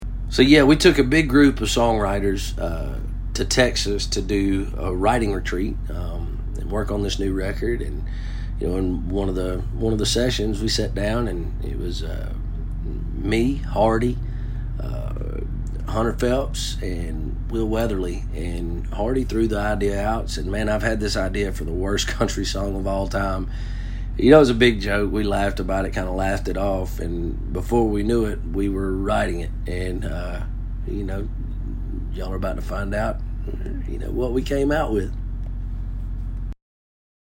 As for how this particular song actually came together, we’ll let Brantley tell it in his own words.